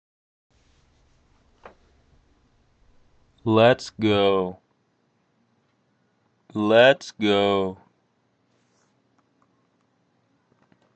Did four takes, said "Lat's Go" roughly 25 times in total. I said the phrase slowly using a deep voice.
There is some high frequency noise in my upload.